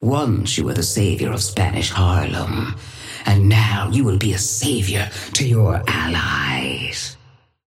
Sapphire Flame voice line - Once you were the savior of Spanish Harlem, and now you will be a savior to your allies.
Patron_female_ally_tengu_start_02.mp3